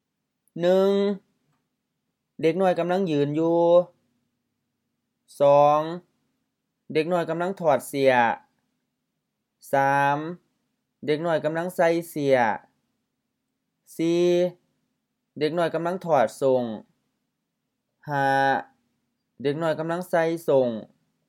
IsaanPronunciationTonesThaiEnglish/Notes